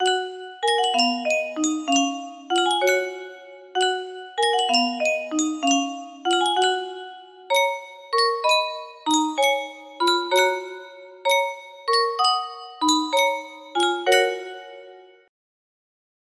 Yunsheng Spieluhr - C.M. Haug O Maria 1450 music box melody
Full range 60